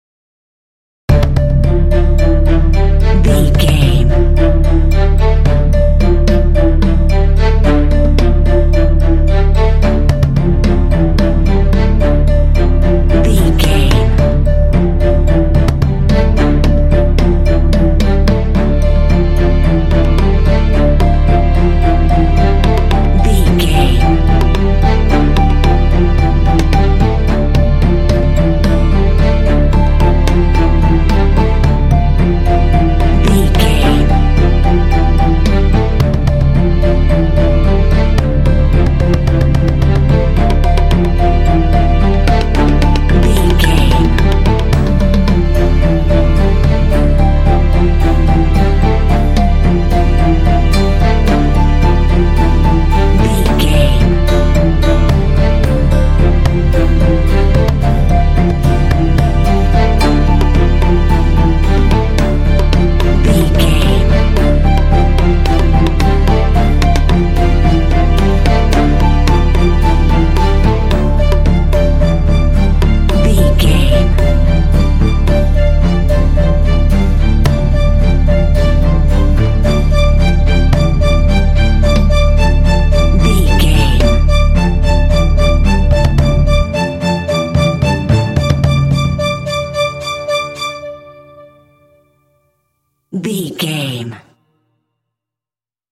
In-crescendo
Thriller
Aeolian/Minor
D
dark
foreboding
ominous
strings
drums
synthesiser
cinematic